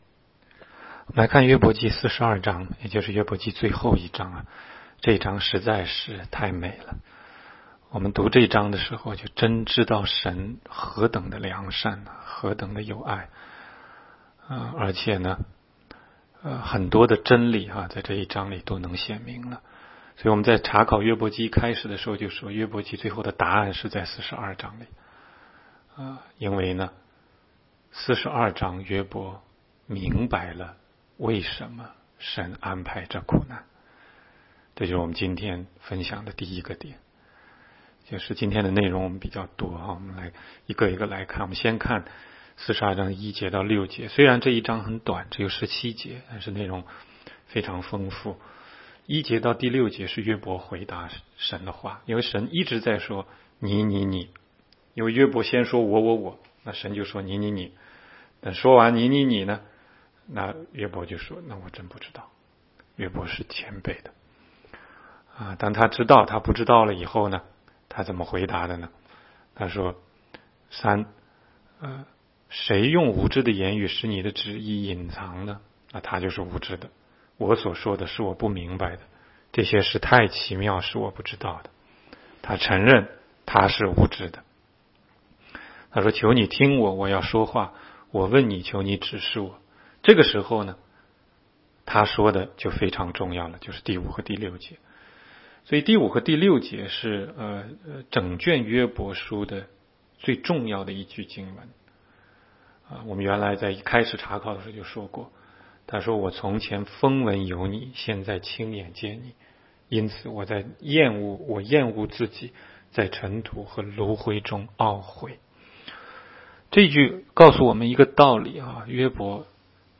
16街讲道录音 - 每日读经-《约伯记》42章